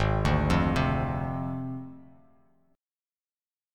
Listen to G#6b5 strummed